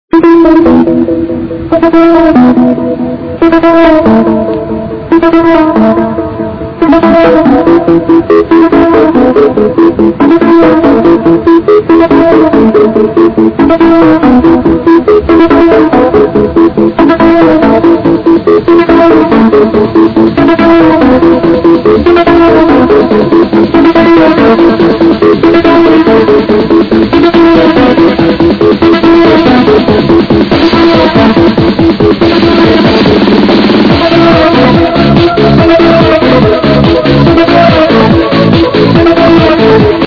sounds very commercial, and very amateurish tbh.